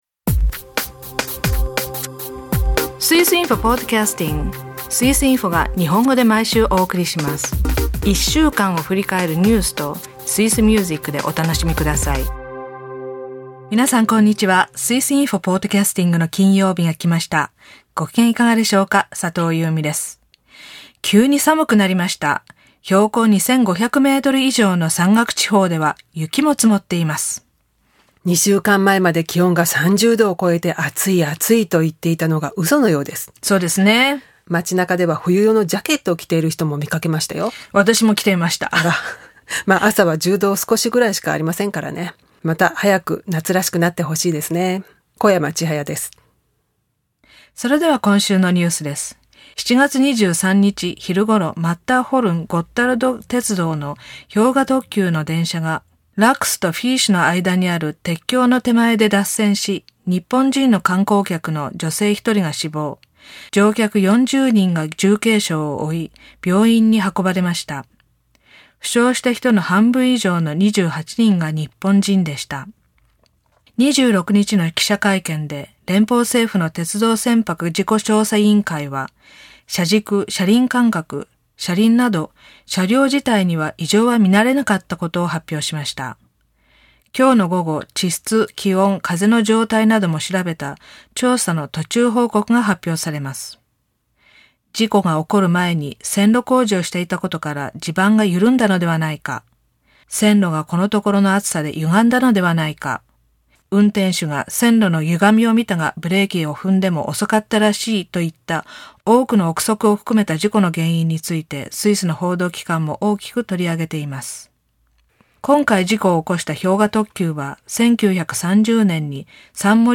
朗読、ウーリは自分をだましたマルコーニに偶然再会します。